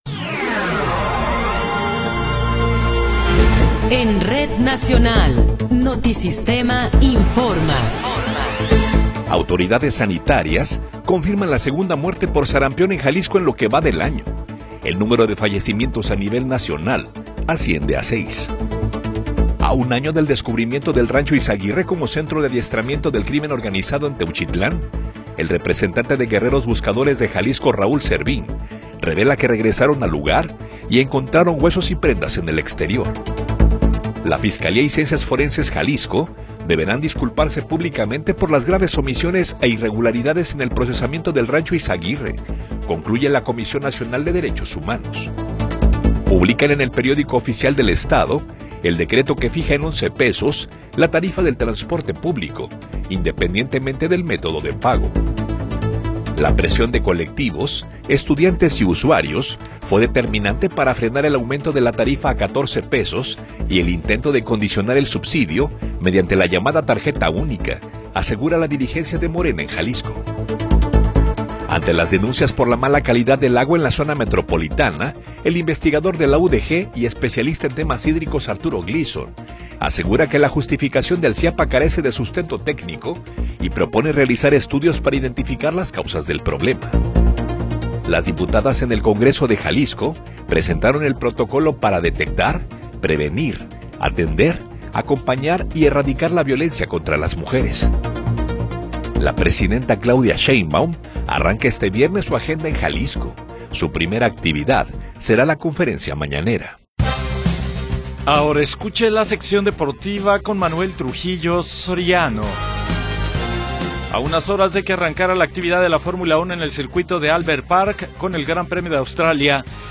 Noticiero 21 hrs. – 5 de Marzo de 2026
Resumen informativo Notisistema, la mejor y más completa información cada hora en la hora.